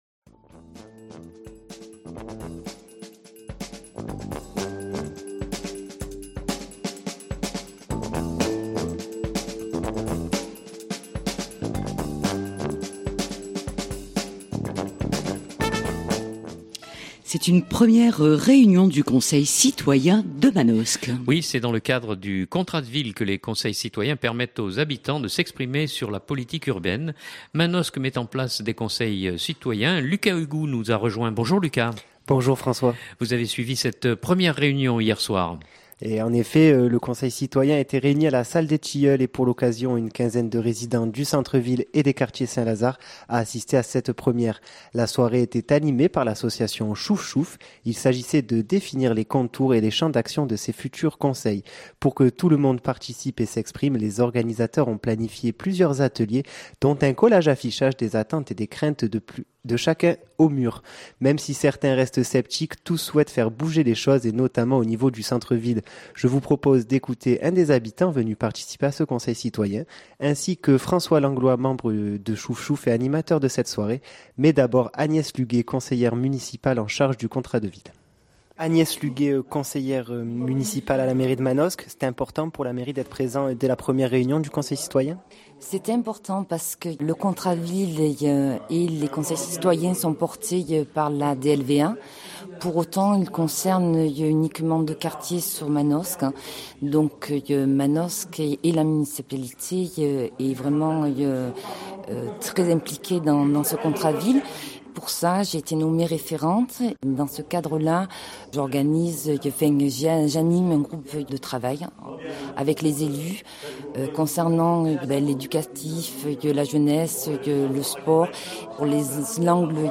Mais d'abord Agnès Lhuguet conseillère municipale en charge du contrat de ville.